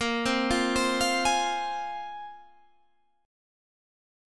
A#7sus2 chord